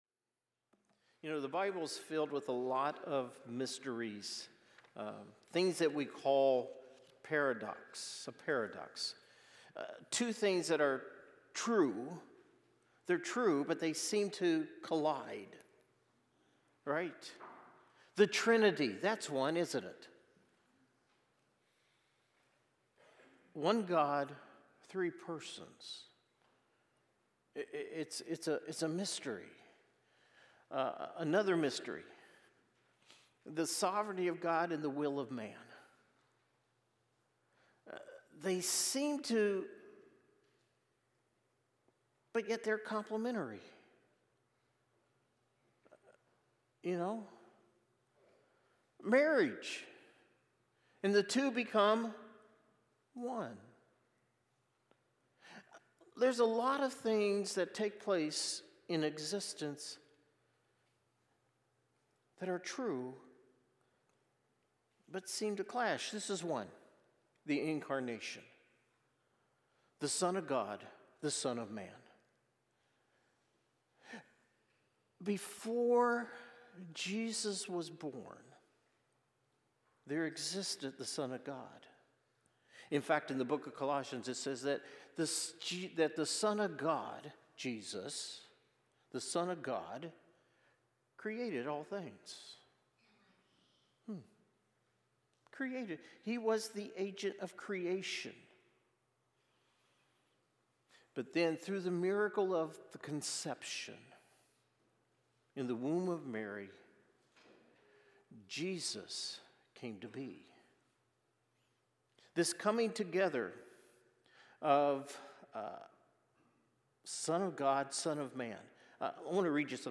Sermons | Salt Creek Baptist Church